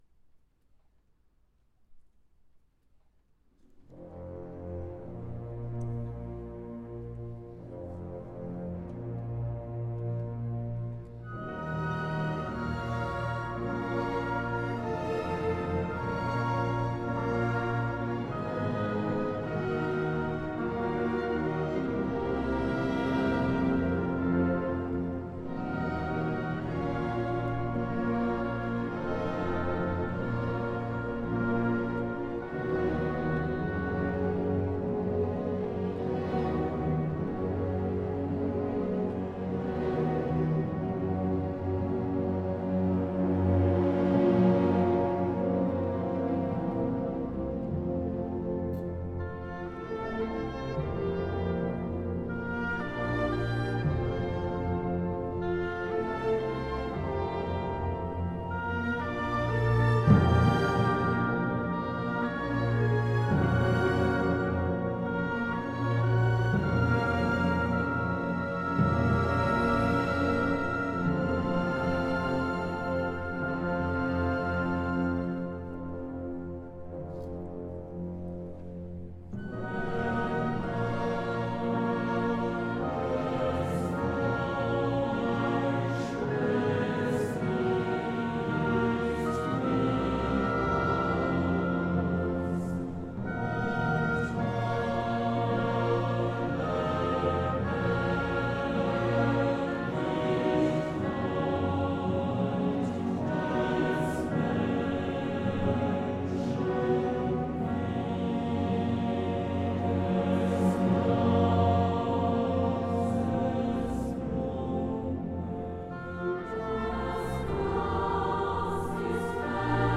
von Chor und Orchester des Collegium musicum der Universität Bonn